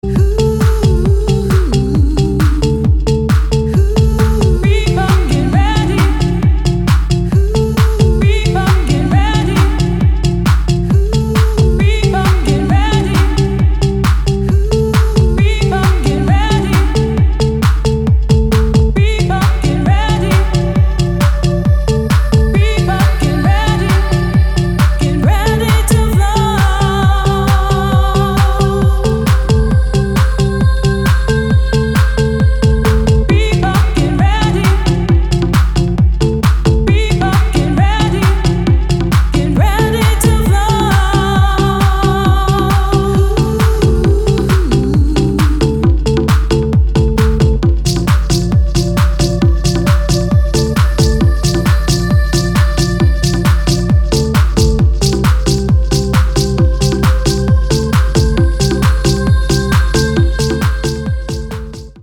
• Качество: 256, Stereo
красивые
dance
электронная музыка
спокойные
vocal trance